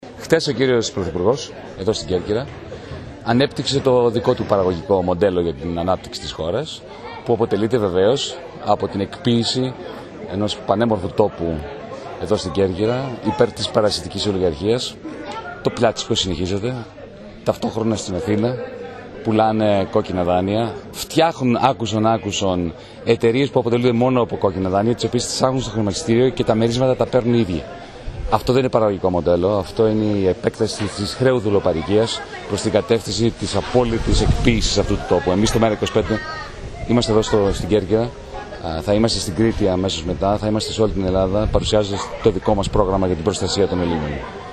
Αυτά ανέφερε ο γραμματέας του ΜέΡΑ25 στην ομιλία του χθες στο χωριό Λιαπάδες, όπου είχε συνάντηση σε ταβέρνα της περιοχής με φίλους και μέλη του κόμματός του.